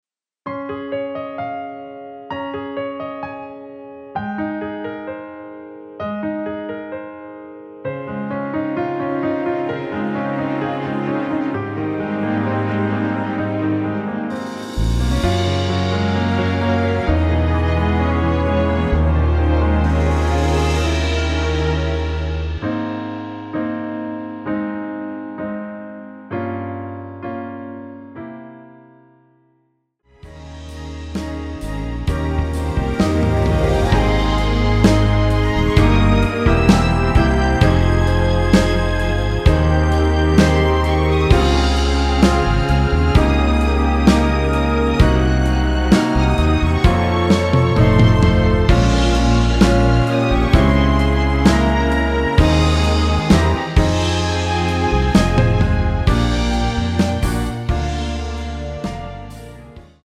Ab
앞부분30초, 뒷부분30초씩 편집해서 올려 드리고 있습니다.
중간에 음이 끈어지고 다시 나오는 이유는